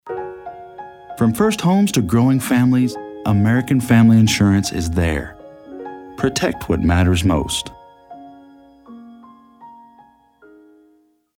Never any Artificial Voices used, unlike other sites.
E=learning, Corporate & Industrial Voice Overs
Adult (30-50) | Older Sound (50+)